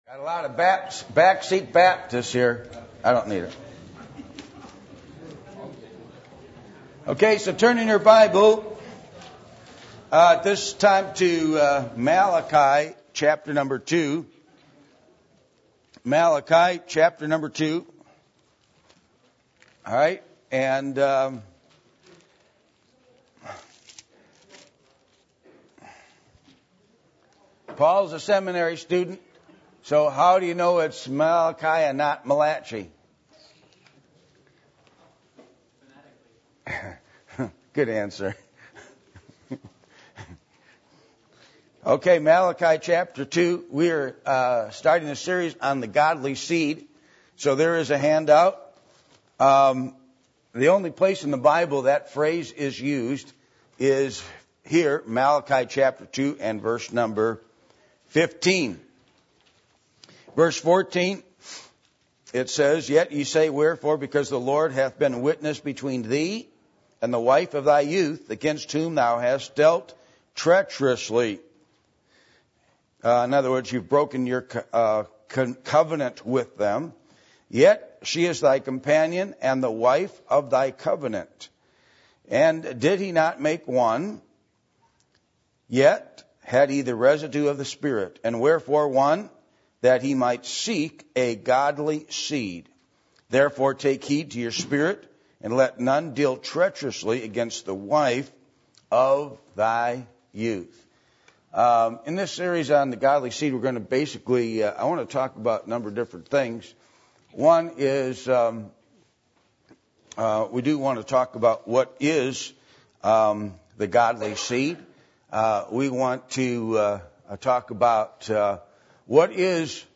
Micah 2:15-16 Service Type: Adult Sunday School %todo_render% « Baptist Churches